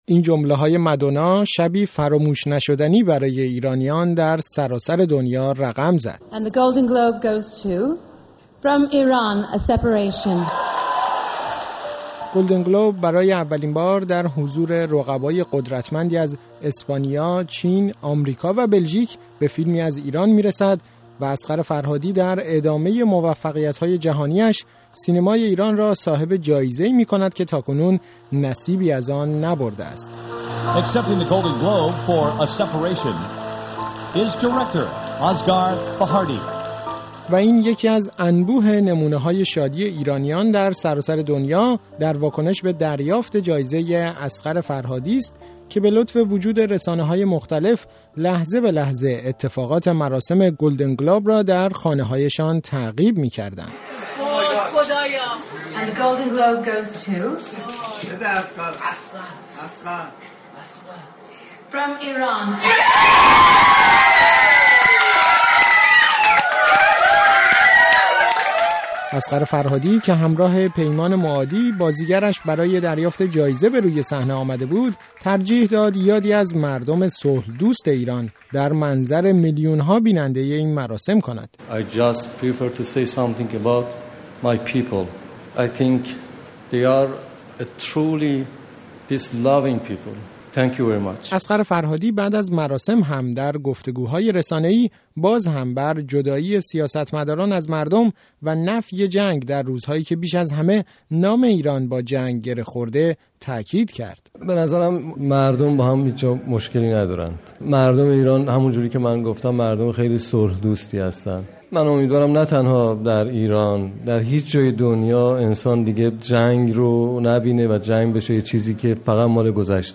گزارش رادیویی